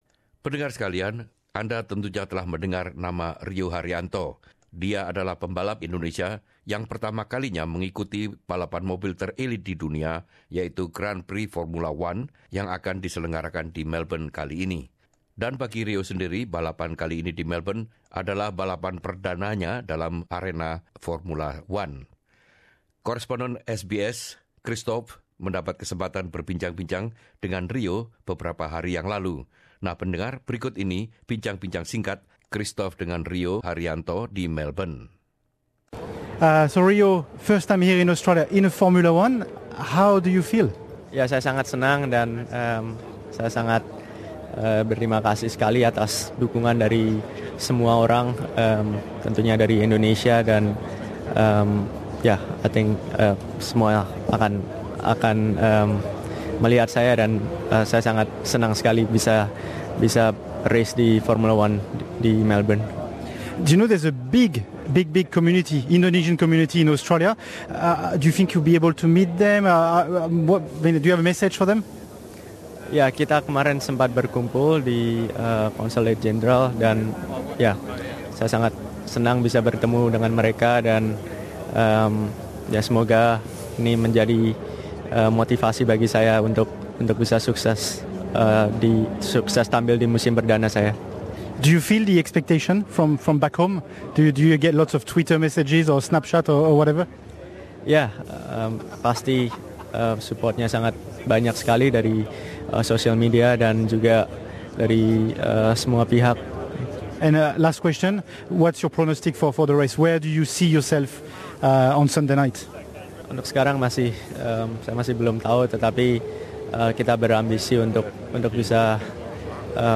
Wawancara singkat dengan Rio Haryanto, pengemudi Indonesia yang pertama kali dalam lomba balap Grand Prix F1 di Melbourne bersama jurnalist Radio SBS